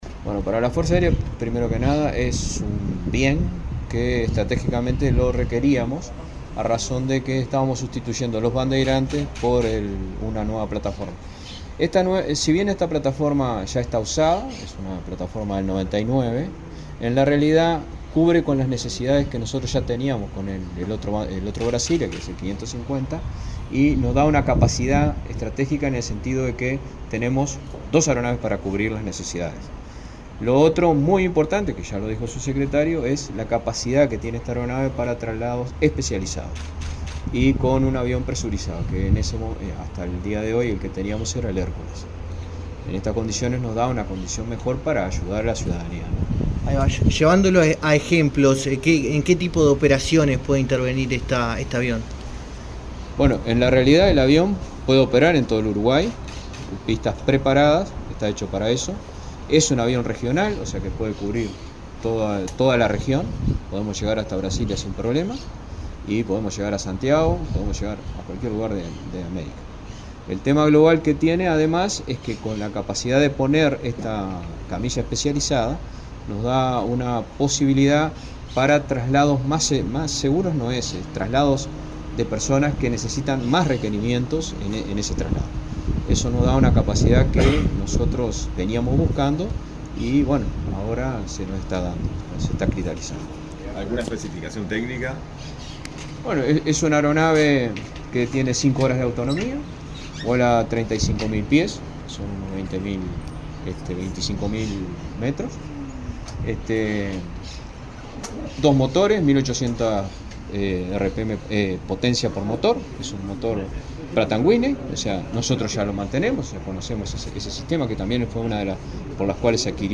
Declaraciones del comandante en jefe de la FAU, Luis Heber De León
Declaraciones del comandante en jefe de la FAU, Luis Heber De León 26/12/2024 Compartir Facebook X Copiar enlace WhatsApp LinkedIn La Fuerza Aérea Uruguaya (FAU) realizó, este jueves 26, la ceremonia de incorporación de un avión Embraer C-120 Brasilia a su flota, asignado al Escuadrón Aéreo n.º 3. El comandante en jefe de la FAU, Luis Heber De León, dialogó con la prensa acerca de las características de la aeronave.